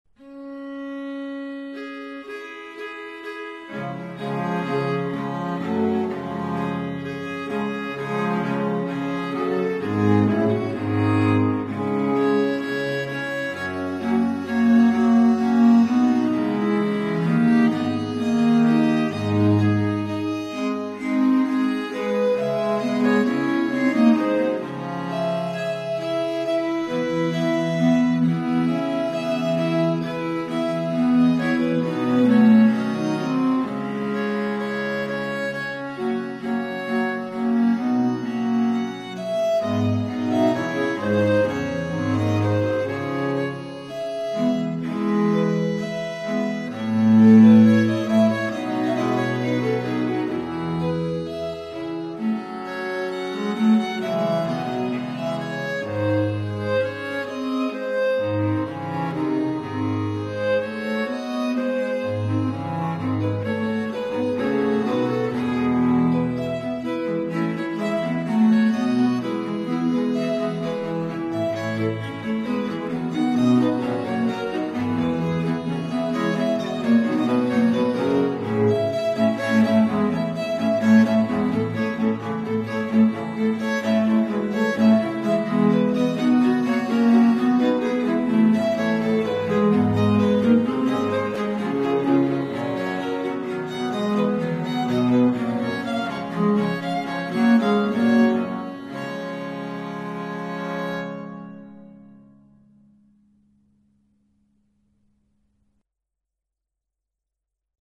Viola da gamba (consort)
performed by viol consort Phantasm
parsons_in_nomine_viol.mp3